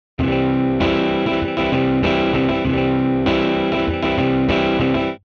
▶Strum Timeをコントロールしながら演奏した例